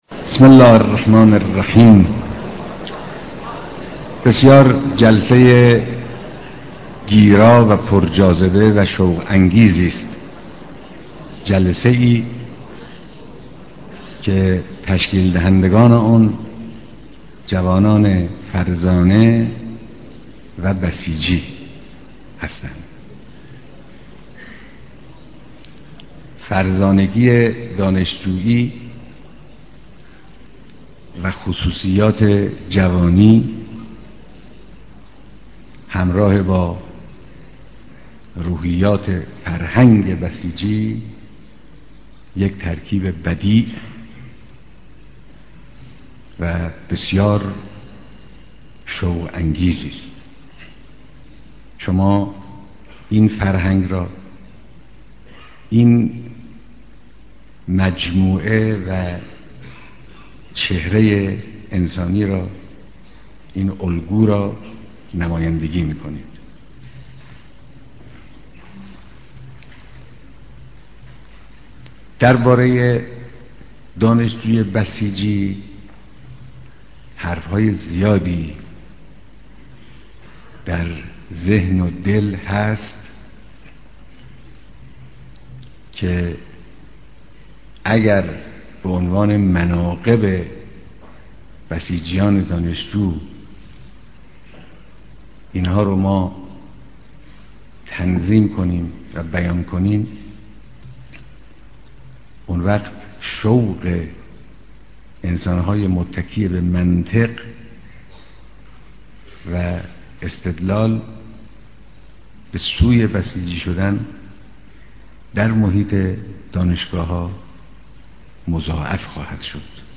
ديدار هزاران نفر از دانشجويان بسيجى